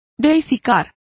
Complete with pronunciation of the translation of deified.